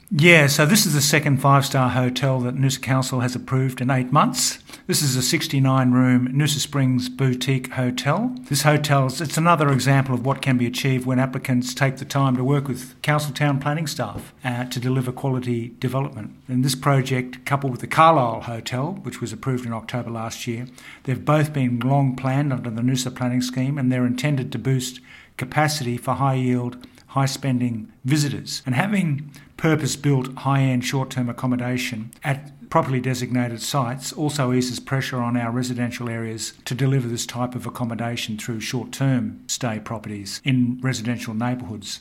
Mayor Frank Wilkie on benefits of the new hotel.
mayor-frank-wilkie-noosa-springs-hotel-approval.mp3